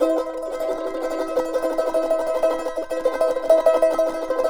CHAR C#MJ TR.wav